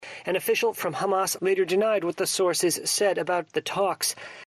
VOAディクテーション：news 231225_07pm ― イスラエル首相、ハマスとの戦闘継続を明言 | ナラボー・プレス